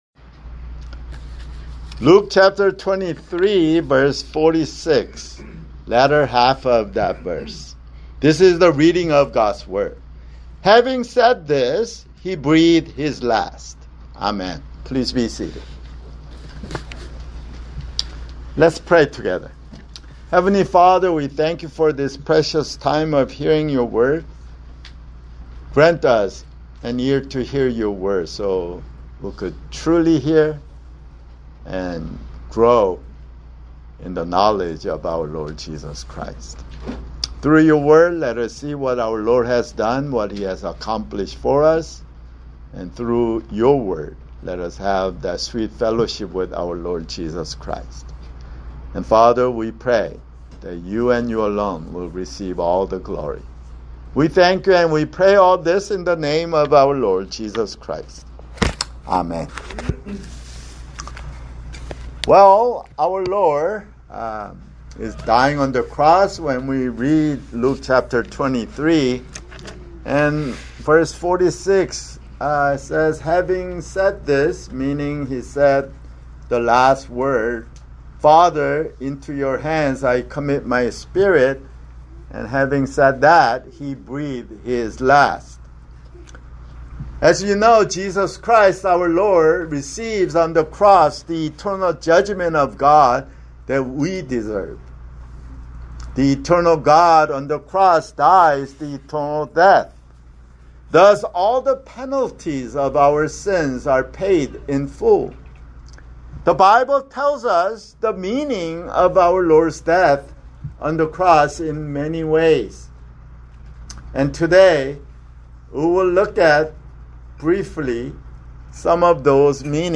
[Sermon] Luke (172)